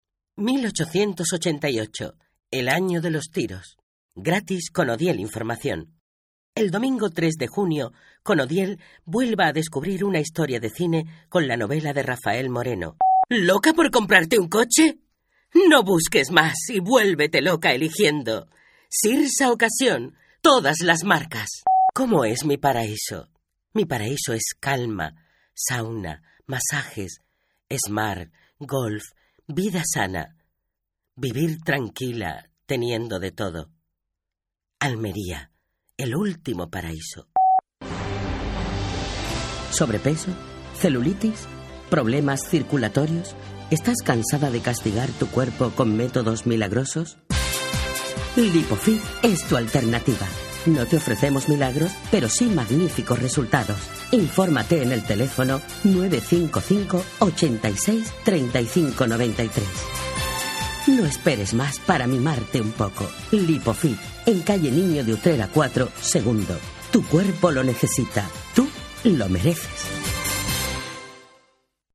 Voces Femeninas